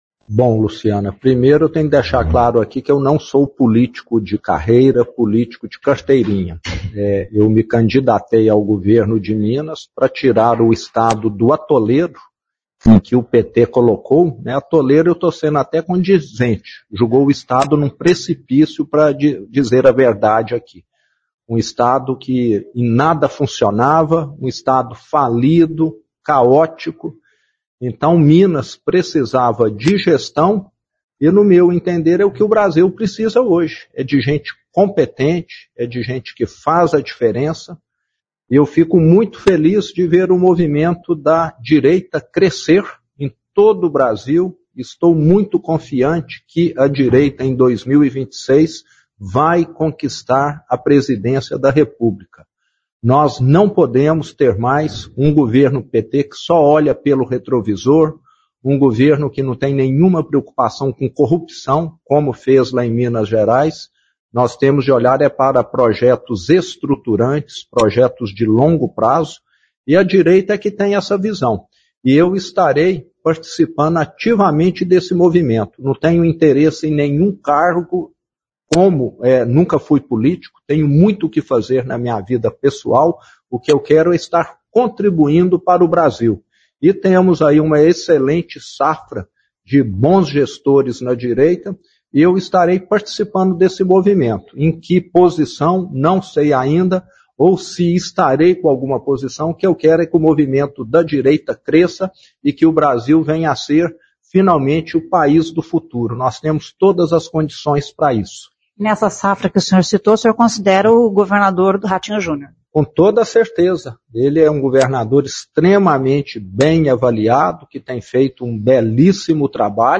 Cumprindo agenda em Maringá, o governador Romeu Zema (Novo), de Minas Gerais, disse que o Brasil tem bons gestores da direita para concorrer à presidência da República em 2026. Questionado se irá disputar a presidência, Zema disse que participará do debate, mas sem interesse por cargos. O governador de Minas disse que Ratinho Jr é um dos grandes nomes para a disputa presidencial.